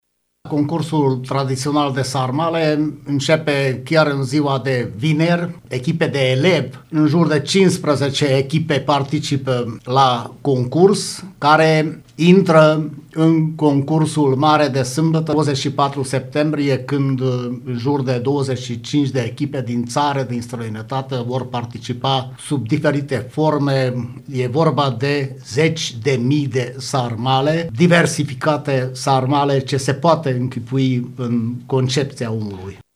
Primarul comunei Praid, Bokor Sándor, în calitate de principal organizator: